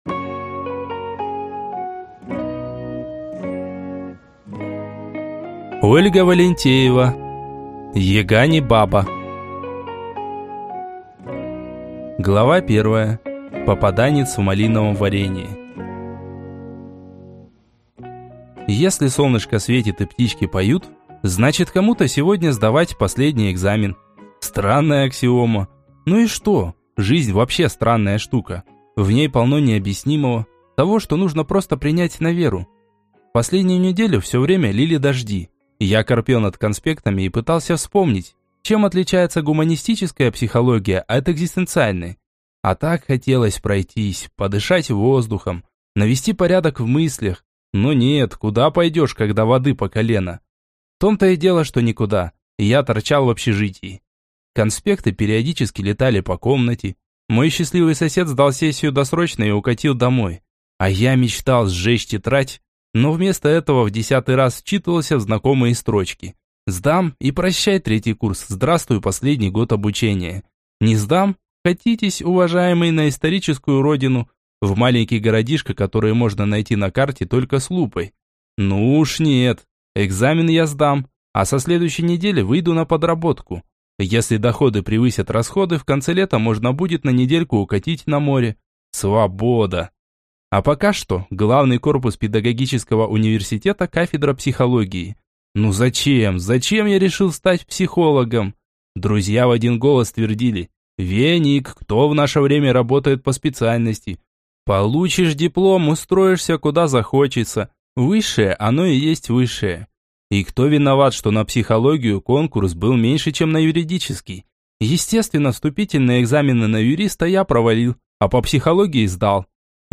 Аудиокнига Яга – не баба!
Прослушать и бесплатно скачать фрагмент аудиокниги